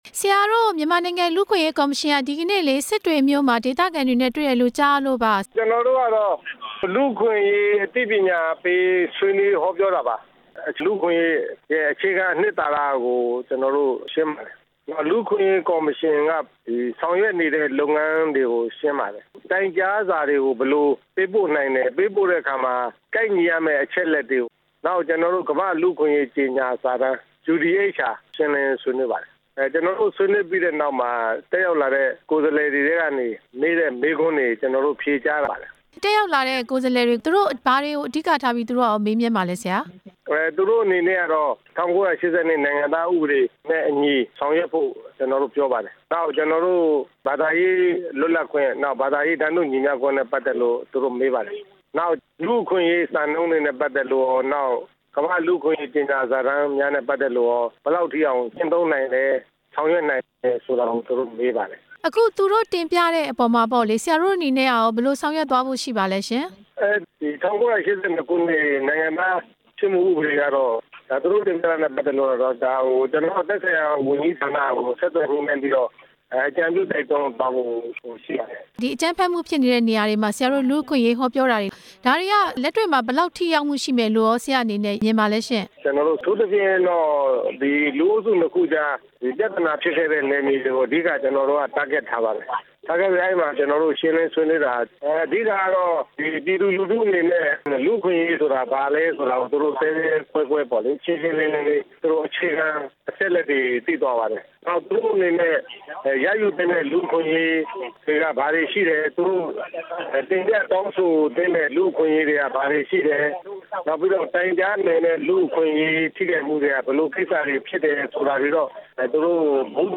ကော်မရှင် အတွင်းရေးမှူး ဦးစစ်မြိုင်နဲ့ မေးမြန်းချက်